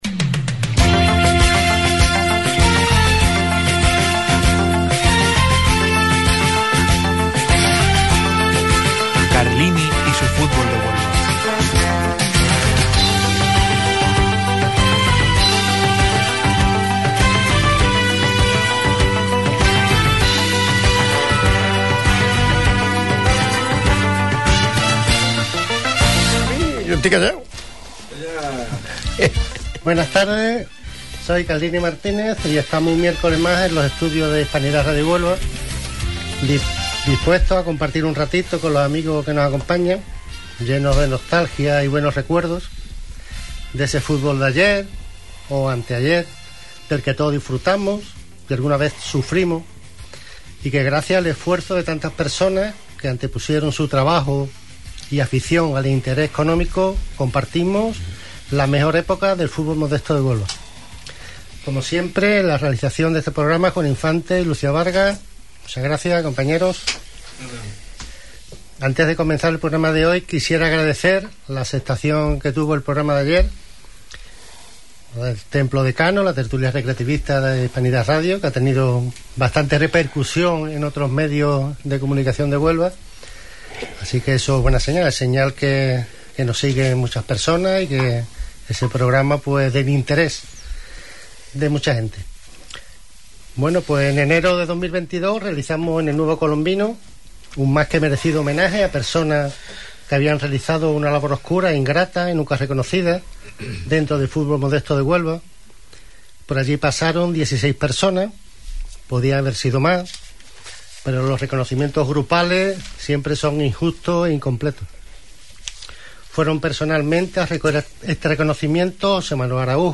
Hoy nos acompañarán antiguos jugadores